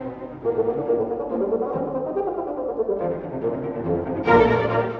↑古い録音のため聴きづらいかもしれません！（以下同様）
（この楽章のファゴットには激ムズシーンがあります）